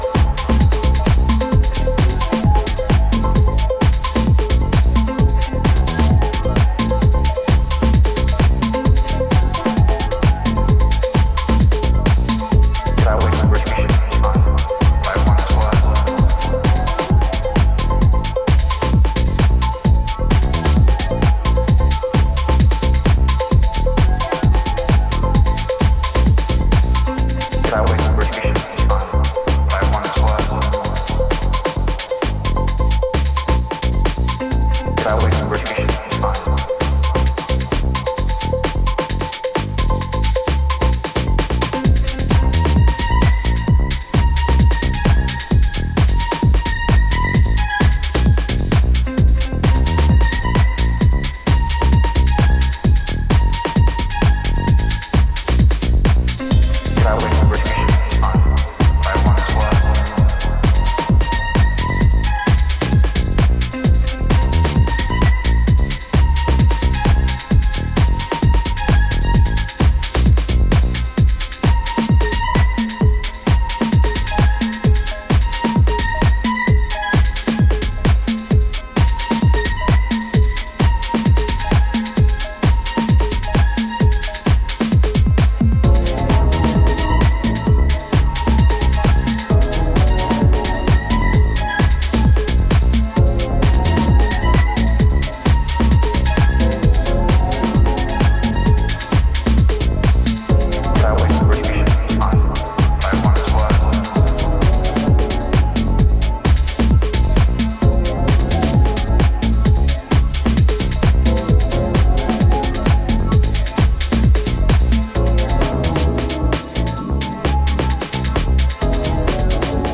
燻んだ音像でブーストするベースラインや凶悪な鳴りのシンセリフ等